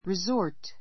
resort rizɔ́ː r t